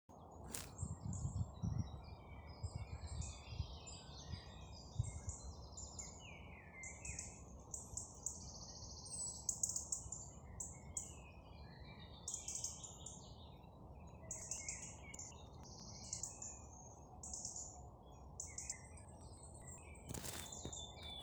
European Robin, Erithacus rubecula
Ziņotāja saglabāts vietas nosaukumsMeža kapi
StatusSinging male in breeding season